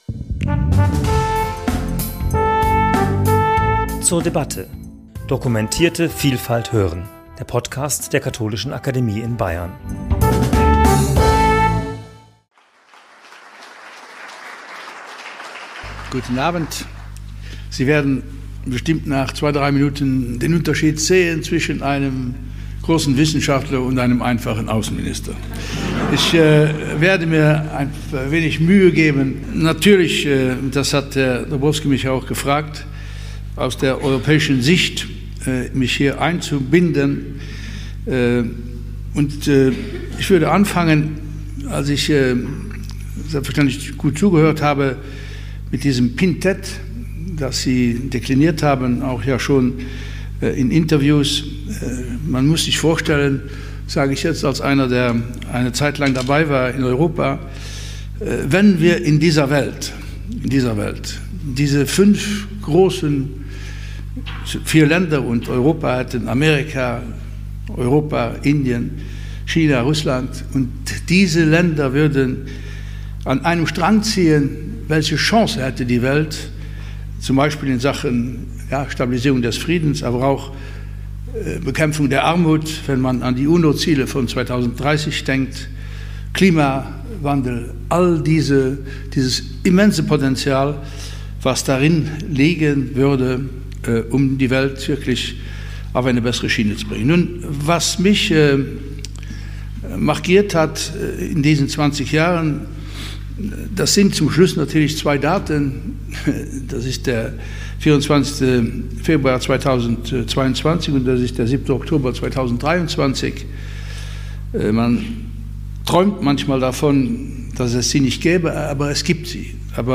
Aktuell hat sich ein System regionaler Einflusszonen herausgebildet, das im Wesentlichen von fünf Machtzentren dominiert wird: den USA, der Europäischen Union, Russland, China und Indien. In seinem Referat am 10.4.2024 in der Katholischen Akademie in Bayern analysiert der ehemalige luxemburgische Außenminister und Vollblut-Europäer, welche Risiken die sich dramatisch veränderte Weltlage - besonders, aber nicht nur für die EU - mit sich bringt.